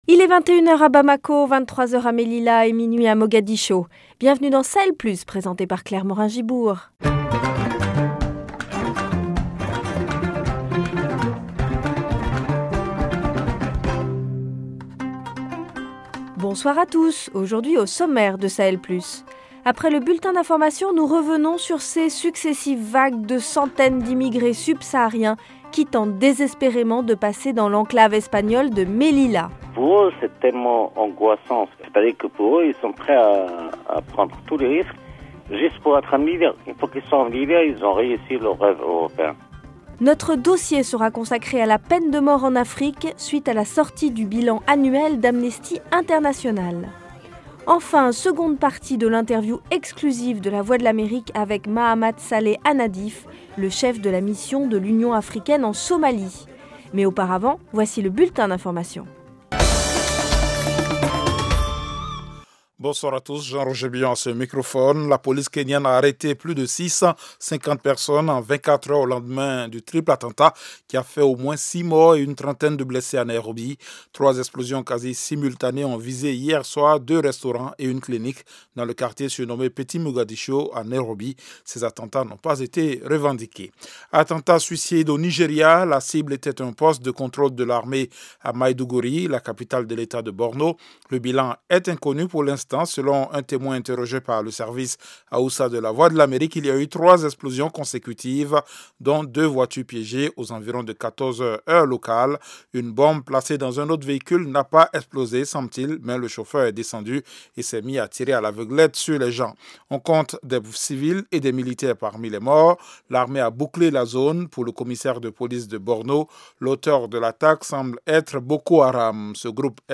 Au programme : l’enclave espagnole de Melila prise d’assaut par les migrants subsahariens qui espèrent pouvoir obtenir un statut de réfugié politique. Dossier : la peine de mort en Afrique suite à la sortie du bilan annuel d’Amnesty International. Seconde partie de l’interview exclusive de la VOA avec Mahamat Saleh Annadif, le chef de l’AMISOM, la Mission de l’Union africaine en Somalie.